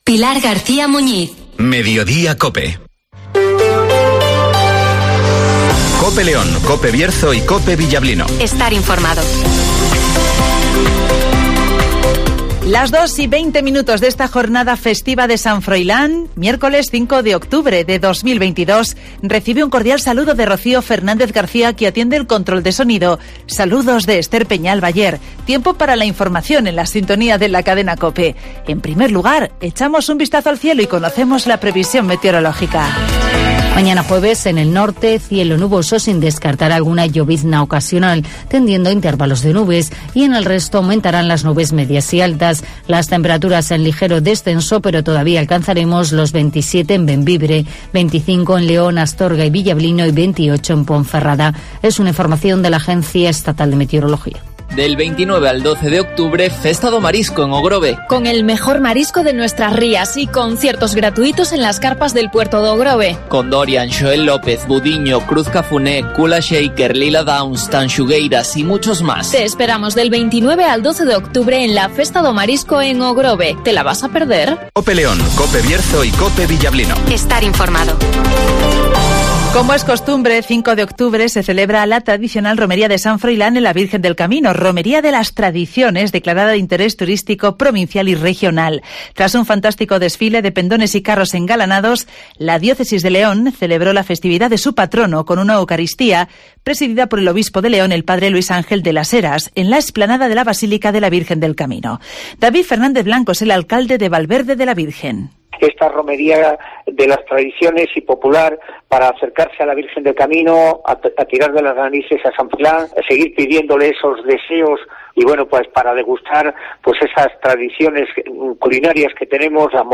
Escucha aquí las noticias con las voces de los protagonistas.